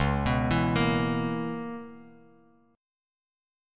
Listen to C#m13 strummed